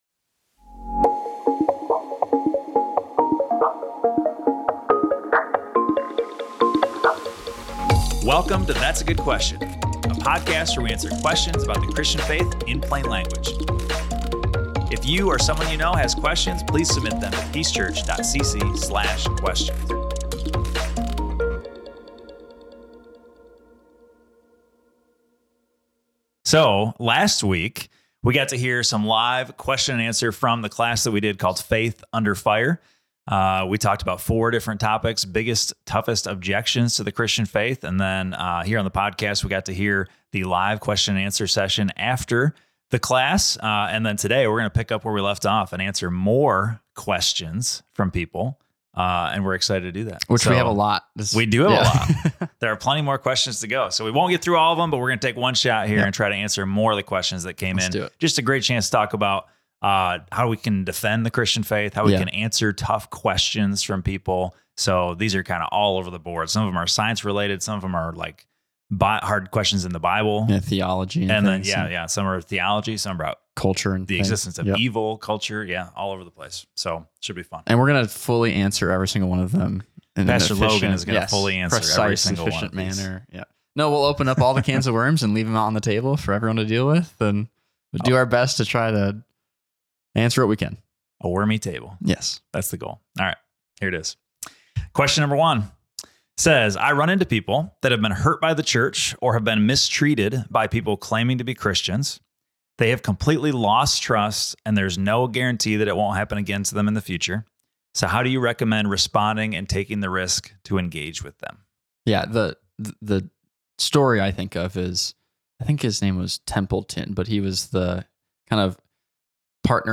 These aren’t just theoretical discussions; they’re conversations that shape how people see faith today. Join us as we continue the conversation and explore how the gospel offers clarity and confidence when our faith is under fire.